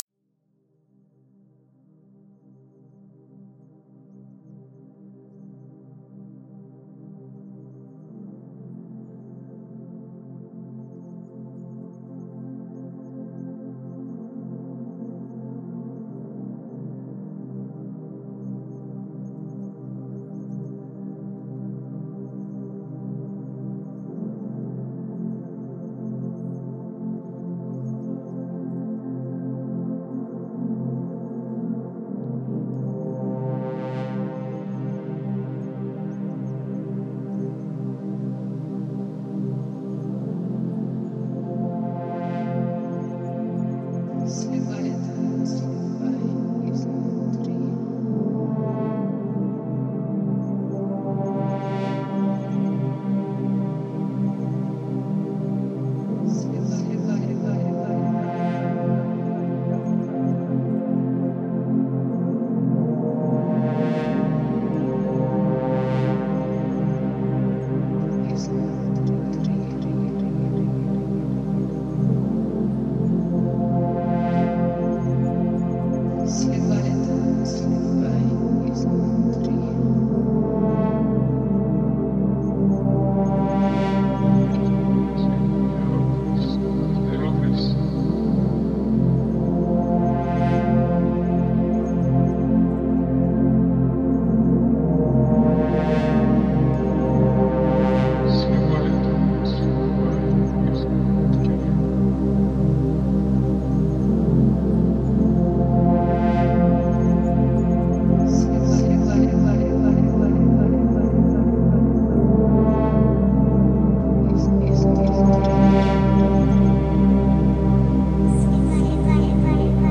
Genre: Ambient.